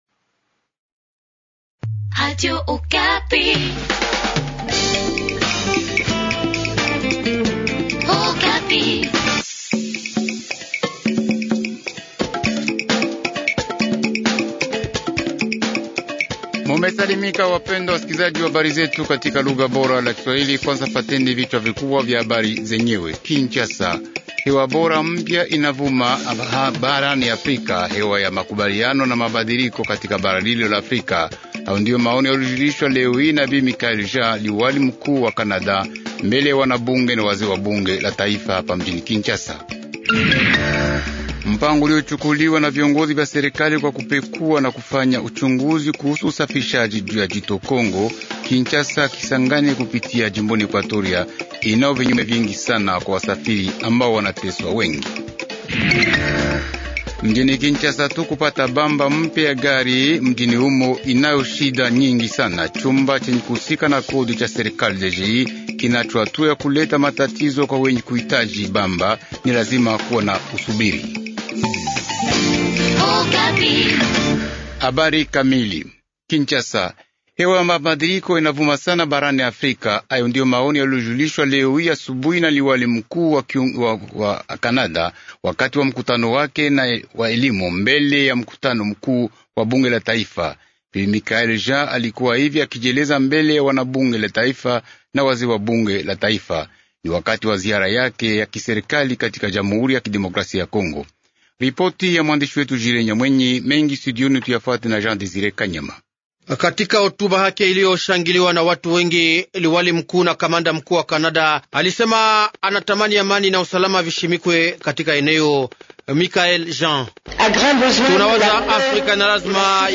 Journal swahili du soir